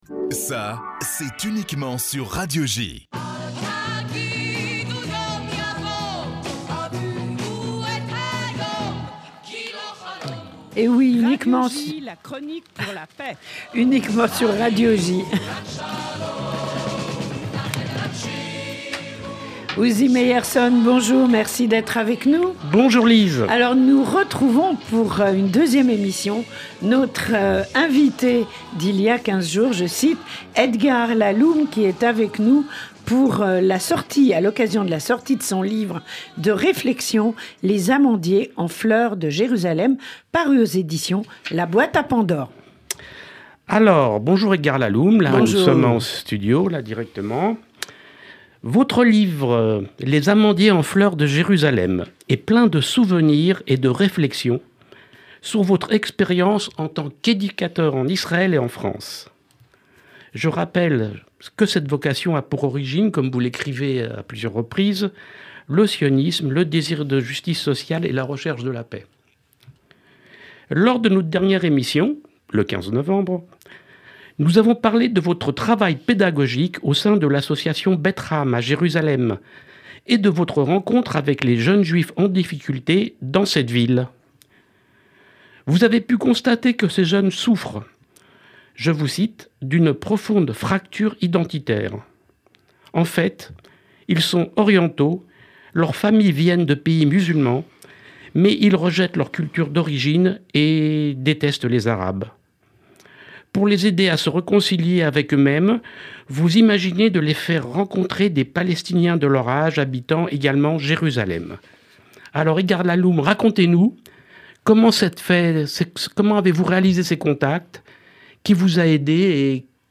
répond aux questions